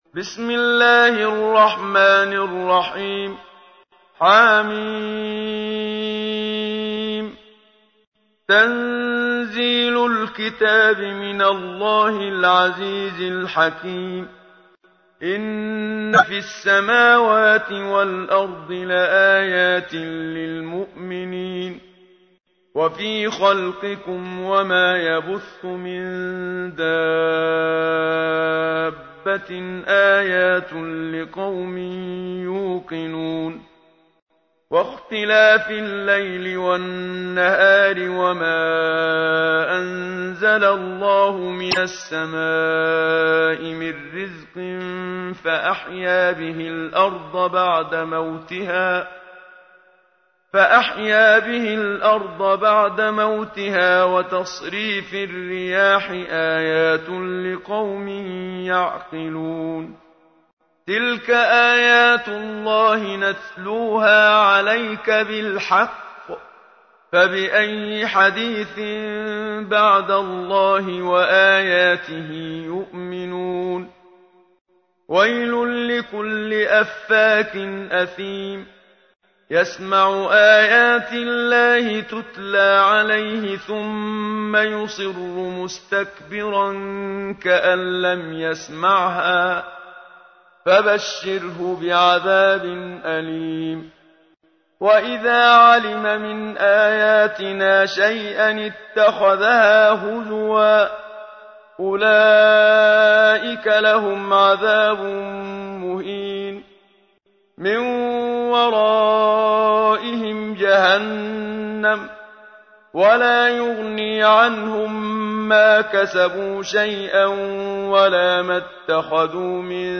تحميل : 45. سورة الجاثية / القارئ محمد صديق المنشاوي / القرآن الكريم / موقع يا حسين